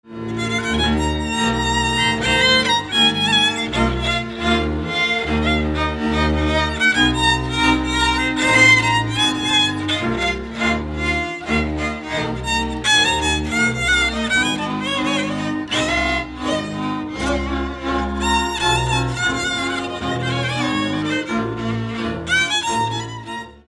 Dallampélda: Hangszeres felvétel
Erdély - Kis-Küküllő vm. - Teremiújfalu
hegedű
kontra (háromhúros)
bőgő
Műfaj: Asztali nóta
Stílus: 1.1. Ereszkedő kvintváltó pentaton dallamok
Kadencia: 4 (4) 4 1